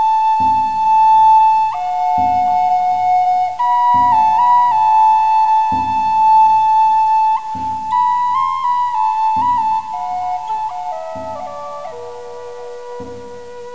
wolf.wav